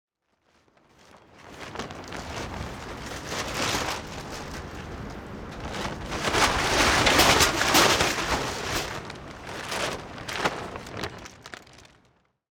tarp.L.wav